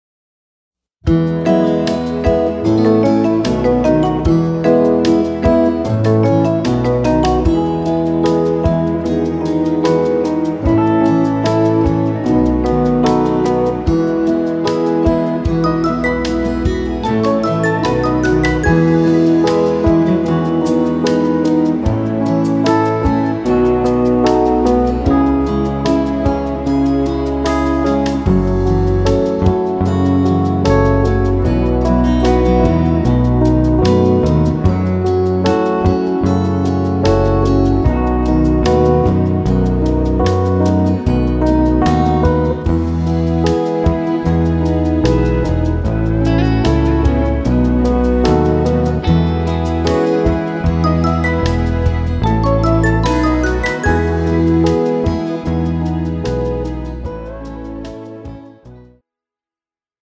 rock ballad style
tempo 75 bpm
key E